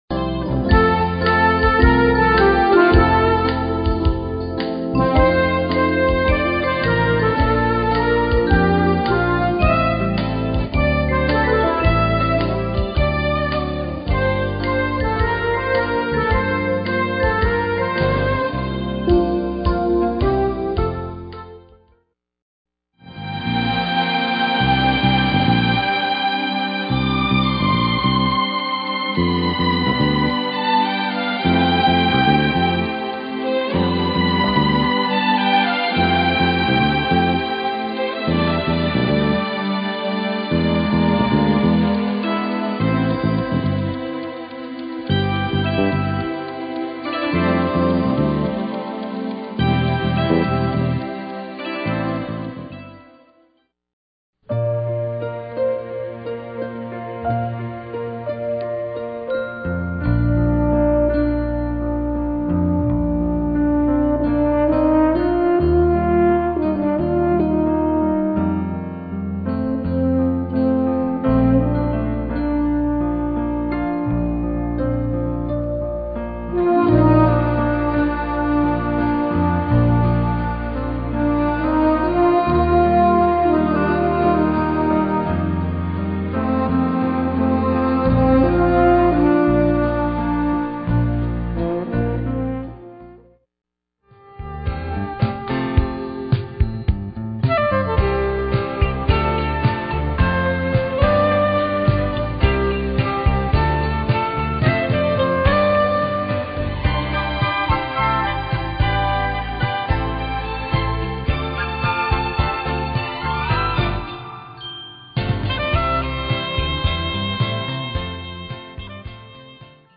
a collection of great hits from movie classics
Broadway & Film